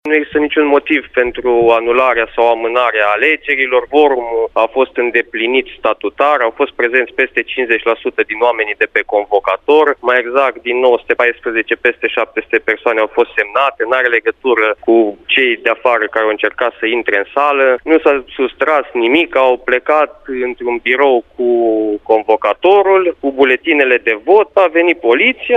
În replică, Raul Ambruș a declarat, pentru Radio Timișoara, că alegerile s-au desfășurat conform statutului, fiind întrunit cvorumul și astfel nefiind niciun motiv ca ele să fie anulate.